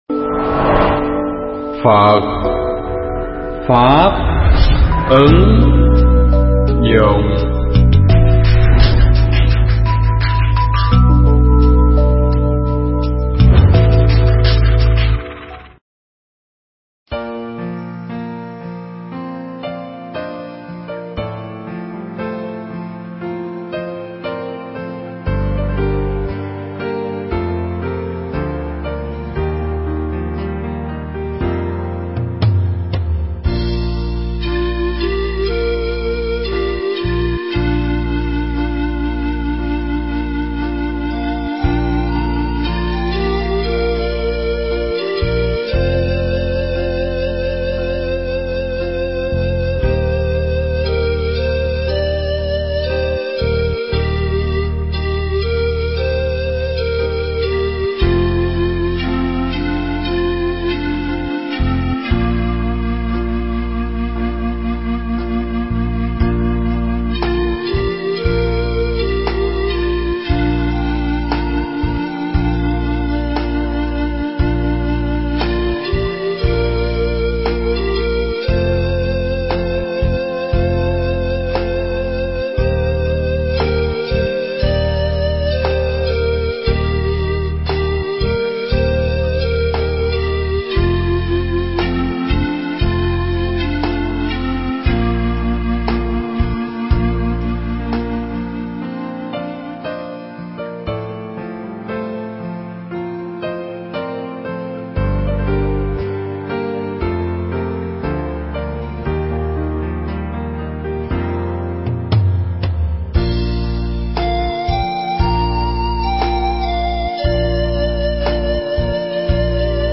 Thuyết pháp Làm Lại Cuộc Đời